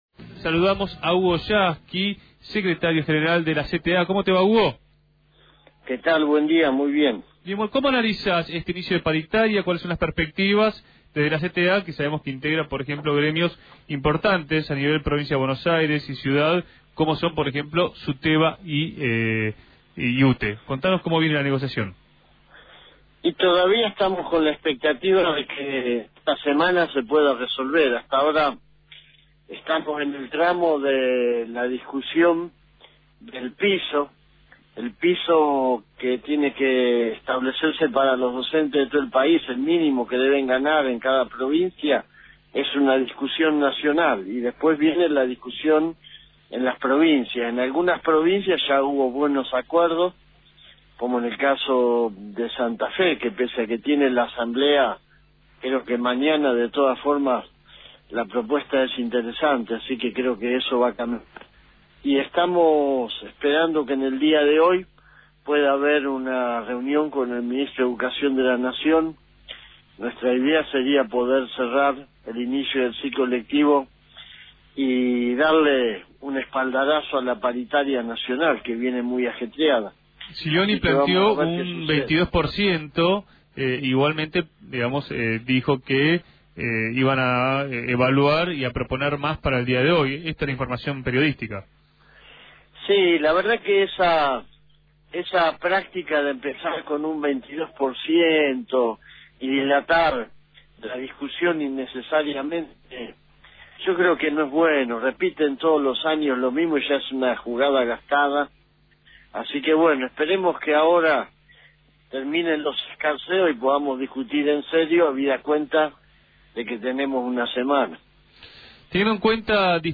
el Secretario General de la CTA afirmó